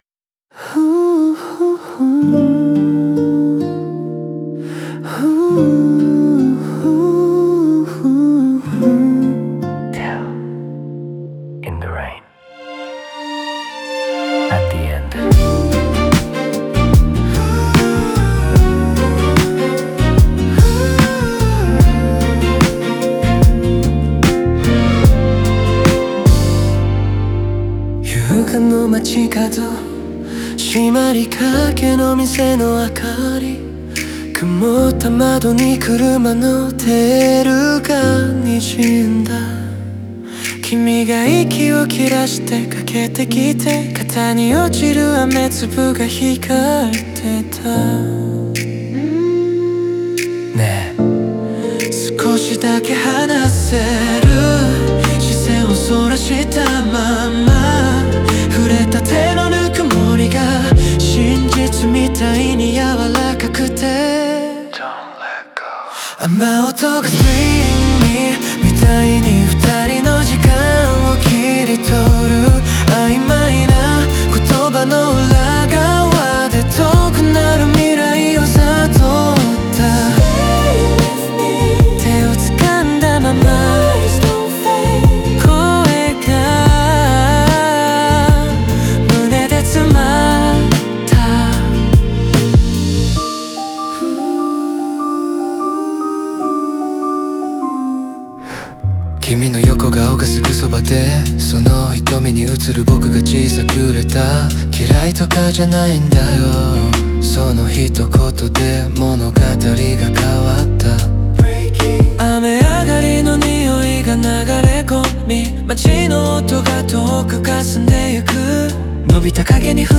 オリジナル曲♪
歌詞は砕けた口調を交えながら日常の中のドラマを描き、温かいハーモニーが感情の揺れを包み込むように構成されている。